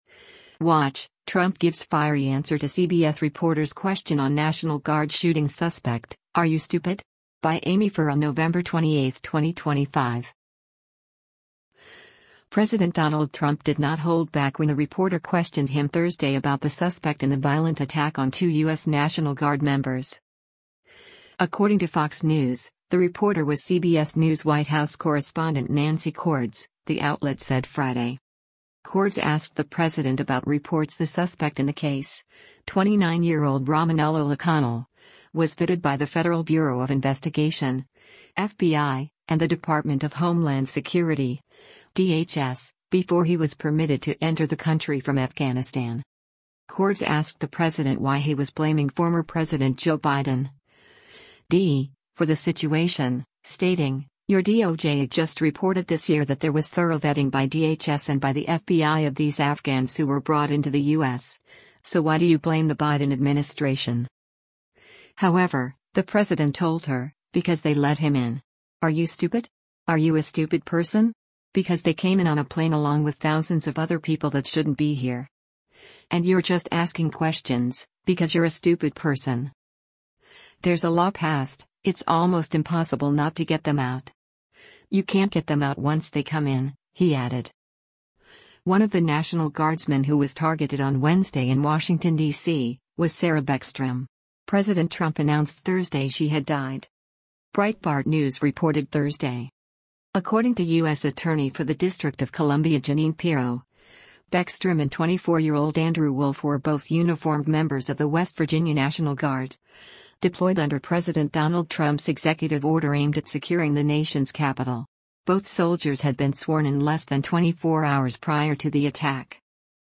WATCH: Trump Gives Fiery Answer to CBS Reporter’s Question on National Guard Shooting Suspect: ‘Are You Stupid?’